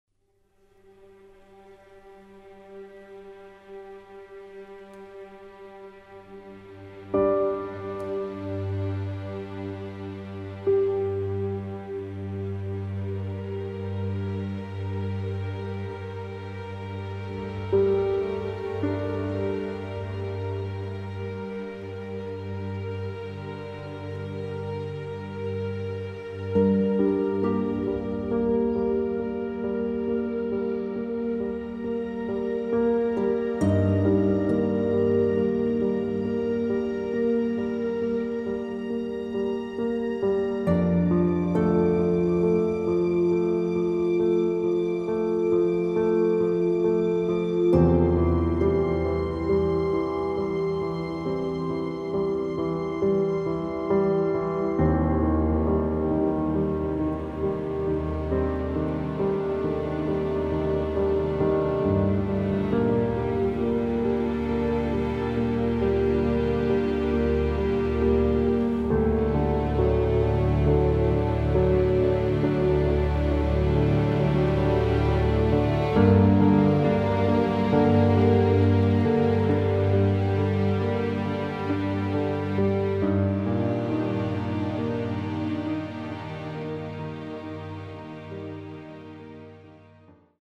an emotionally evocative, thematically rich score